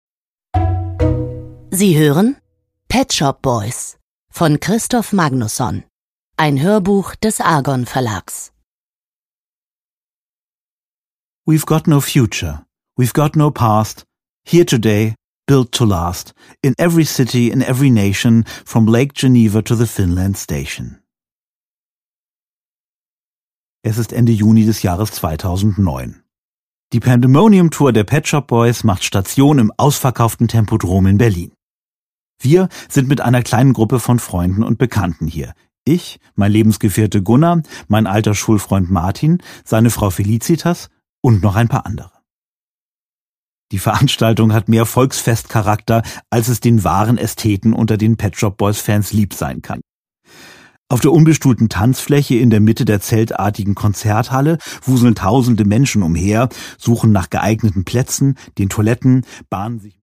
Produkttyp: Hörbuch-Download
Gelesen von: Kristof Magnusson